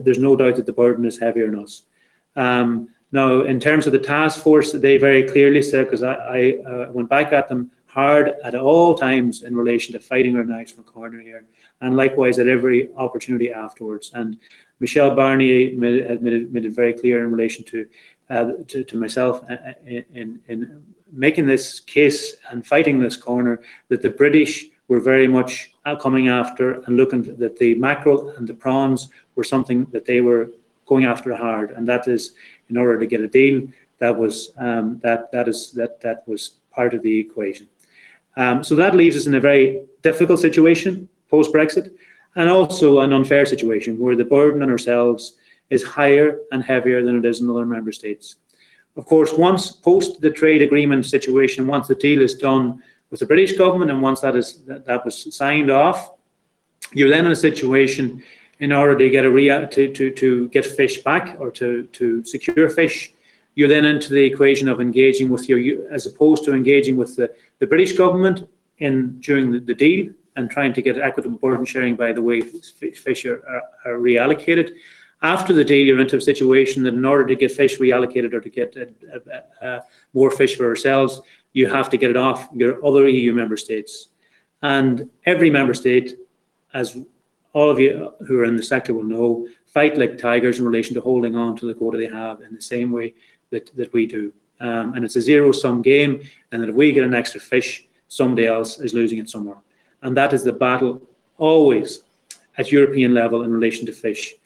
Speaking during a Donegal County Council Fisheries Committee today, Minister Charlie McConalogue says he is doing all he can to alleviate the ‘big burden’ facing local fishermen: